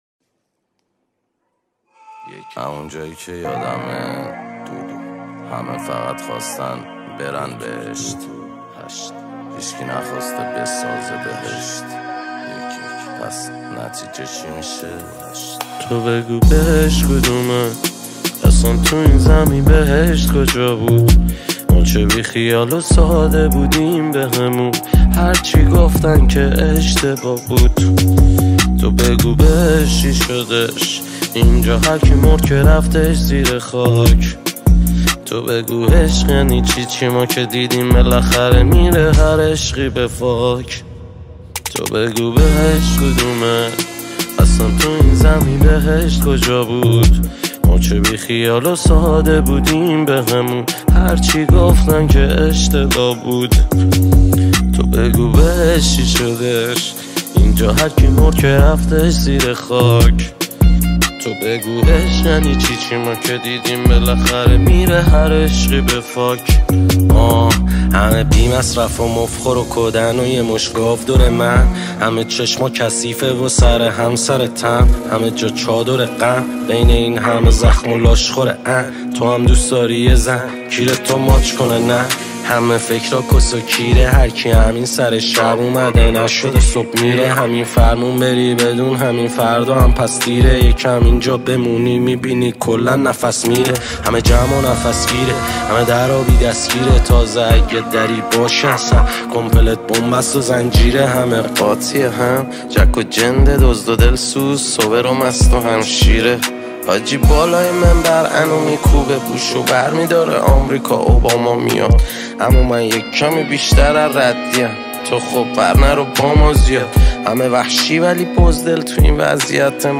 آراَندبی
آهنگ با صدای زن